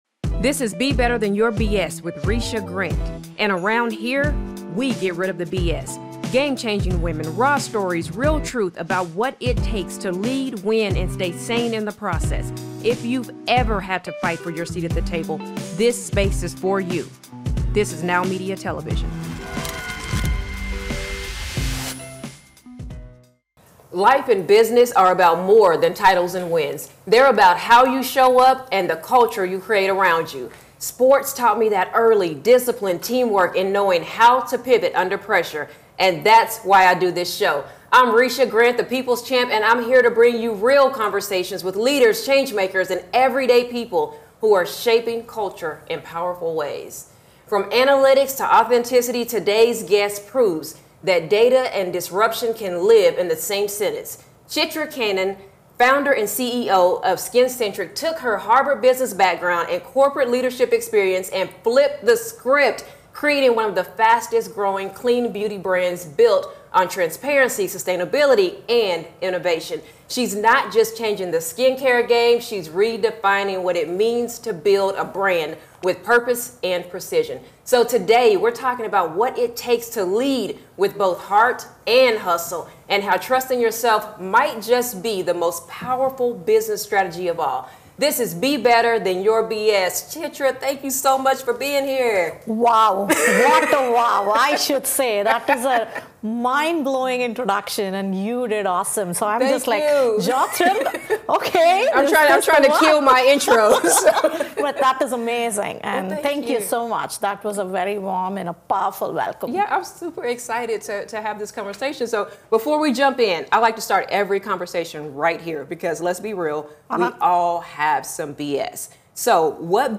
candid conversation